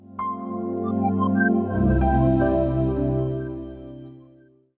Phone_Power_off.wav